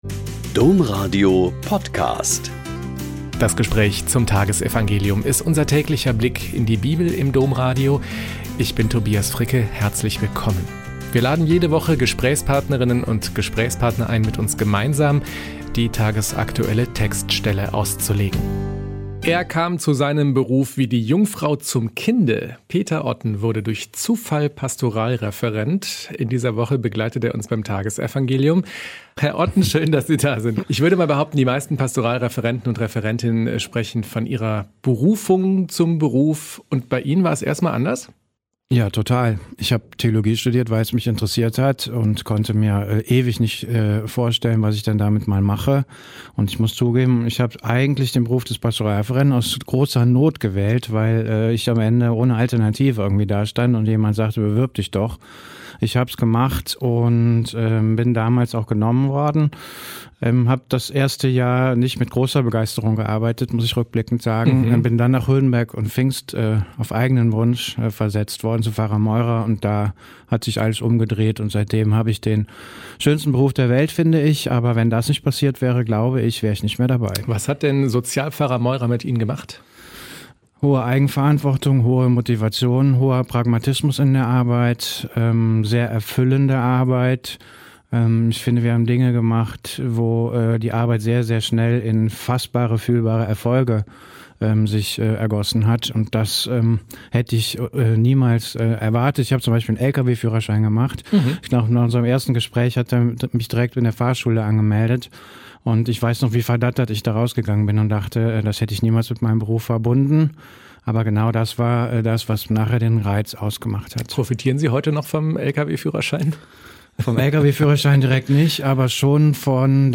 Gespräch